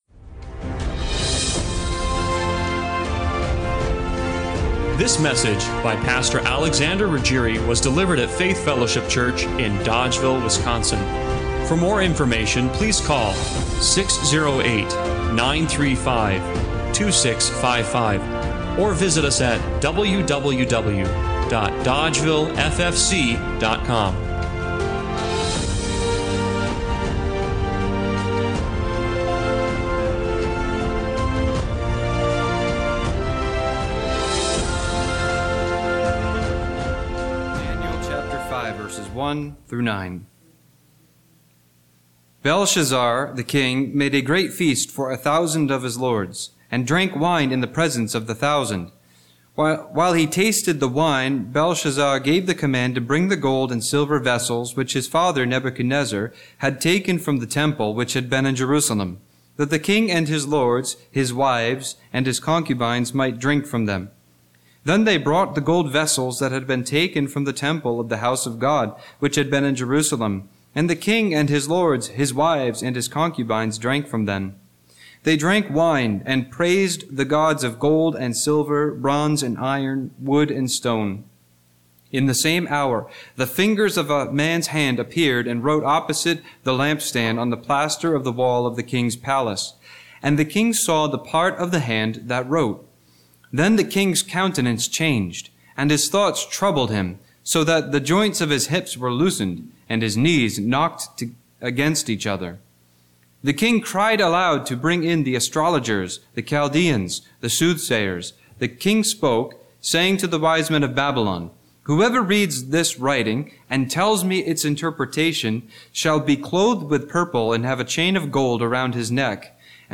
Daniel 5:1-31 Service Type: Sunday Morning Worship Does God’s patience run out?
This spellbinding sermon will wake us up to know which things push God’s buttons.